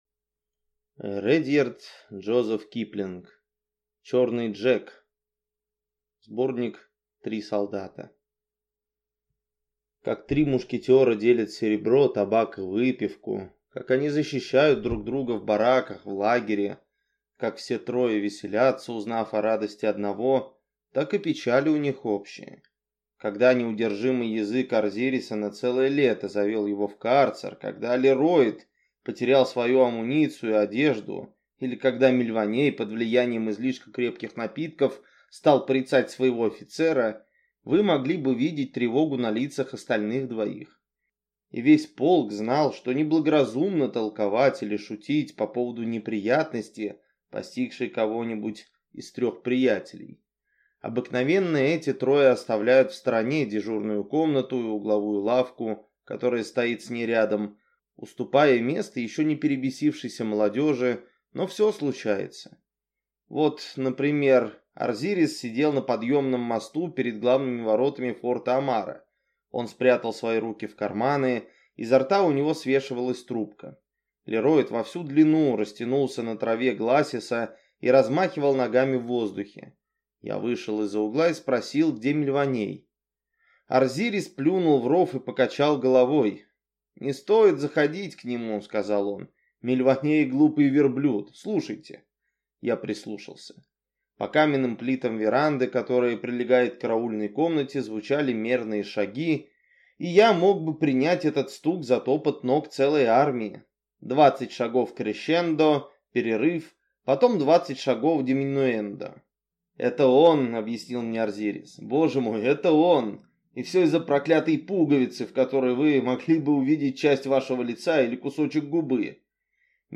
Аудиокнига Черный Джек | Библиотека аудиокниг